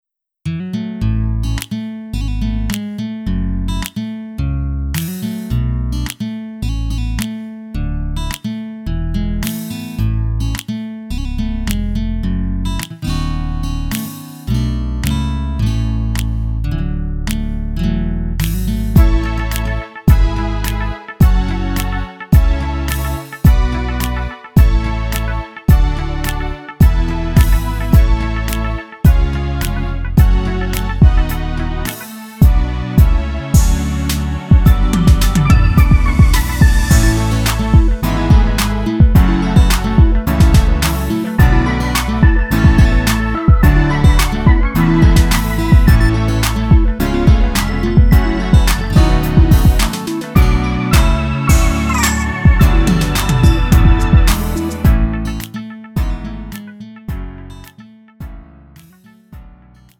음정 원키 2:53
장르 구분 Lite MR